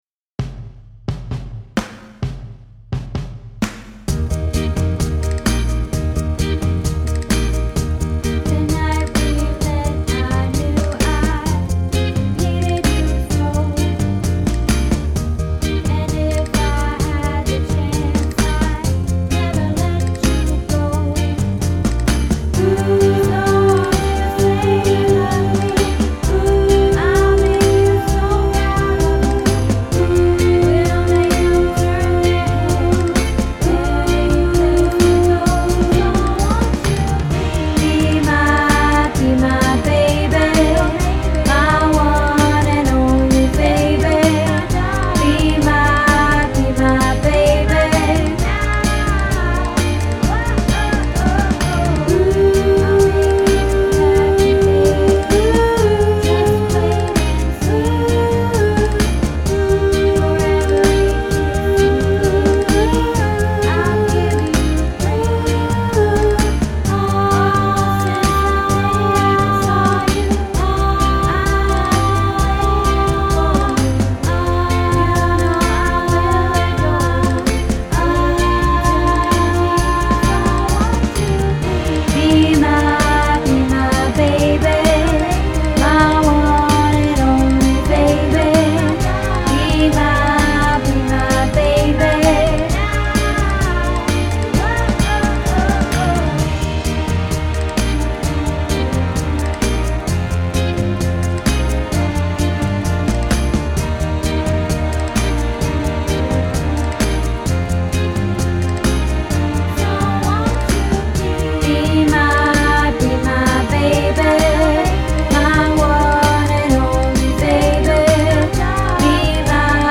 Be My Baby - Tenor